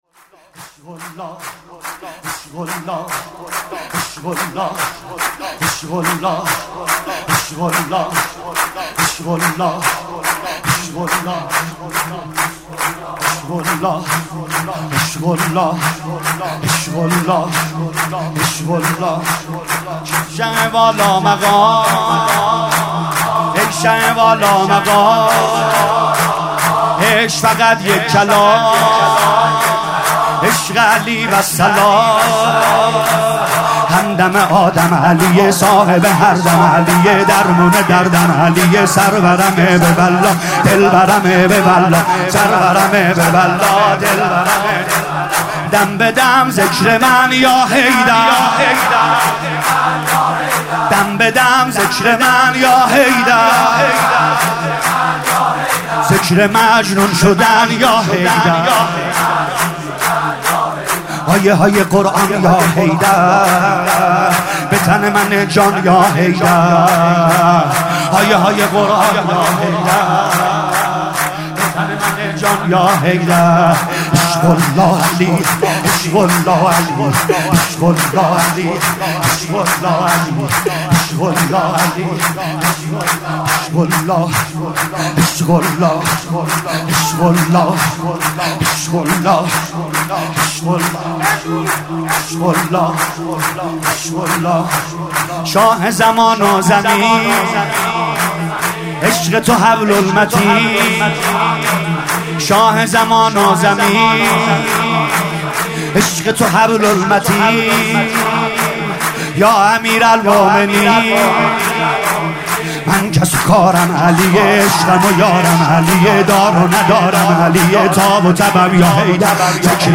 مولودی‌خوانی
برچسب ها: میلاد امام علی ، مولودخوانی